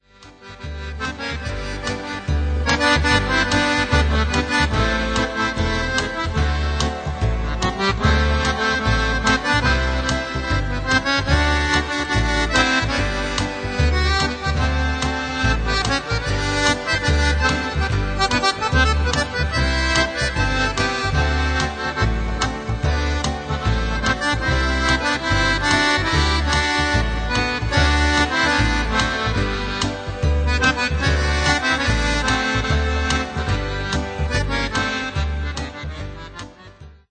Acordeonist convins, dar fara sa abuzeze de acest instrument